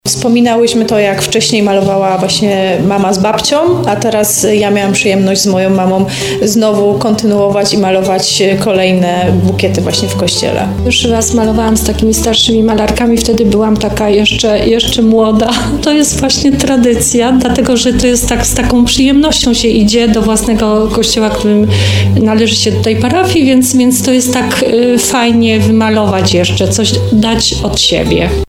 mówiły zalipiańskie malarki